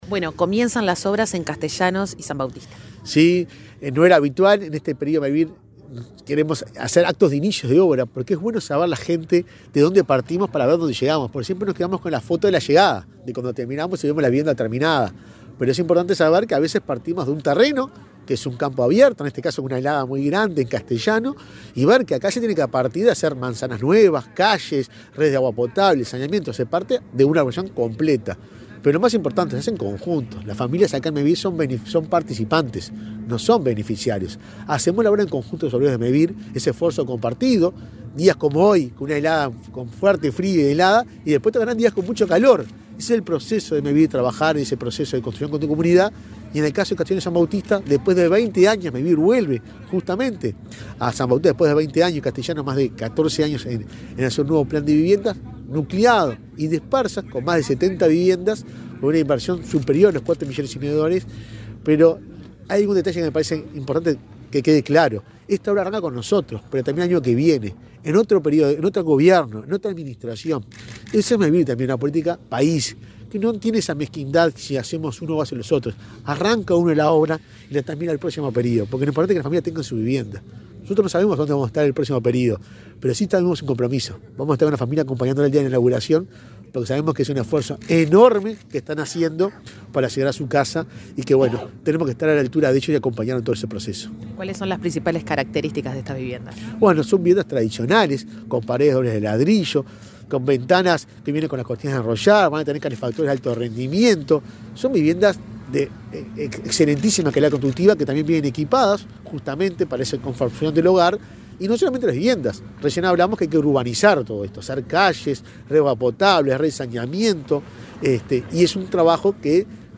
Entrevista al presidente de Mevir, Juan Pablo Delgado
Entrevista al presidente de Mevir, Juan Pablo Delgado 11/07/2024 Compartir Facebook X Copiar enlace WhatsApp LinkedIn El presidente de Mevir, Juan Pablo Delgado, dialogó con Comunicación Presidencial en Canelones, durante la presentación del inicio de obras de 64 viviendas en las localidades de San Bautista y Castellanos.